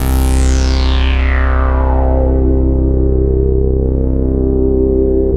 SYN JD-8001R.wav